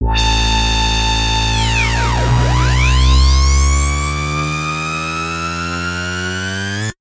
描述：只是一个来自一些贝司的上升循环。
标签： 140 bpm Dubstep Loops Fx Loops 1.18 MB wav Key : Unknown
声道立体声